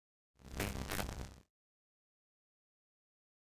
meleeattack-swoosh-magicaleffect-group01-lightning-00.ogg